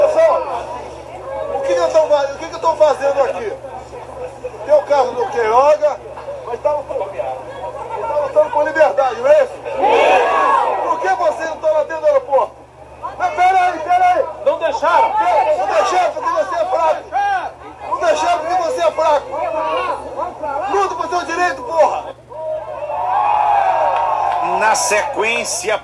A agenda do ex-presidente Jair Bolsonaro (PL) na Paraíba começou com confusão já no desembarque dele na manhã desta sexta-feira (12/04) no Aeroporto Castro Pinto.